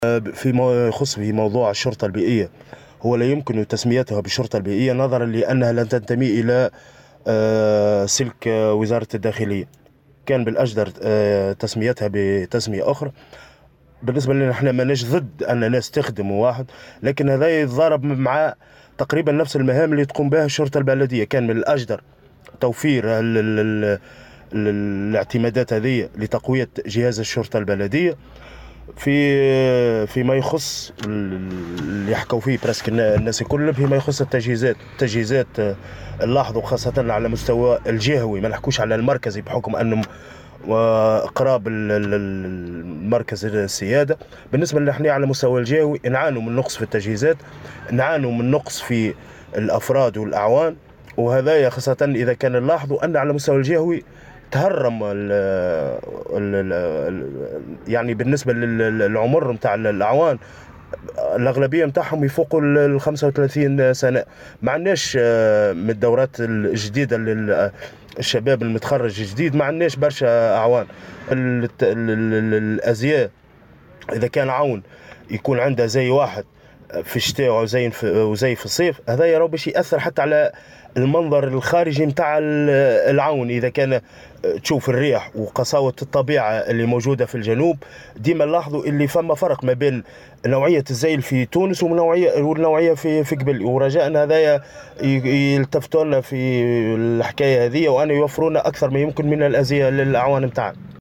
في تصريح لمراسل "الجوهرة أف أم"